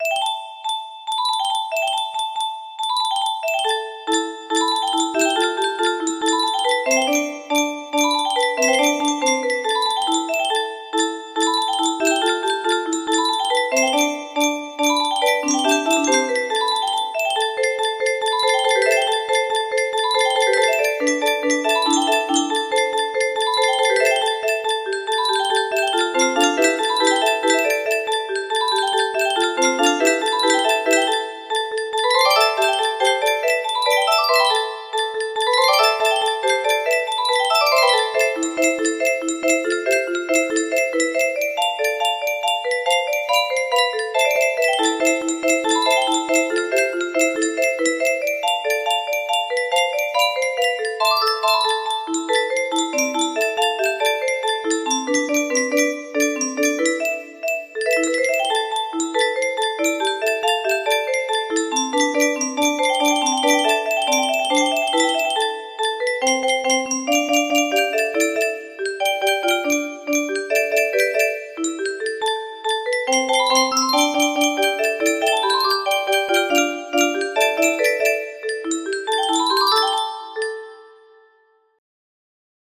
Short version of the classic disco fun